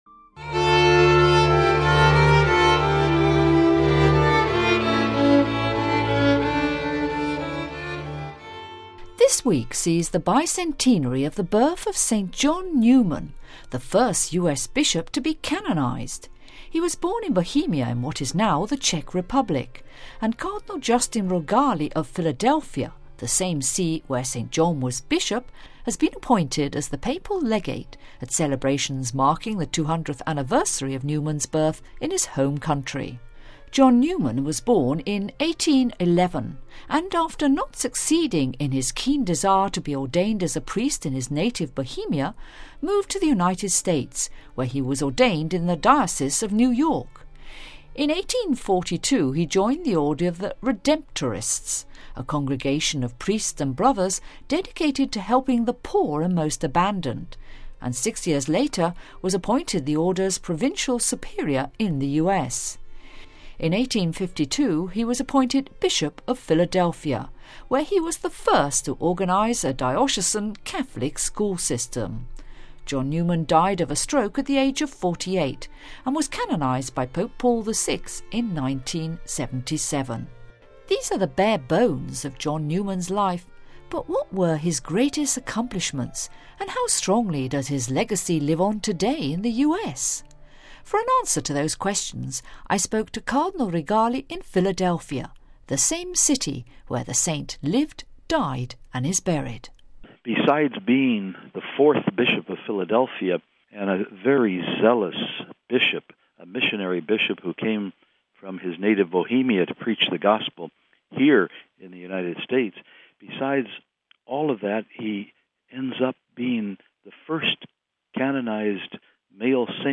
The Cardinal went on to say that St John Neumann was a bishop "of whom we're all very very proud" and whose memory "is very much alive" as shown by "the constant flow of pilgrims to his shrine."